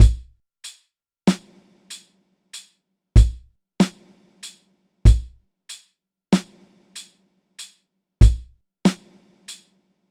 Drum Loop 5.wav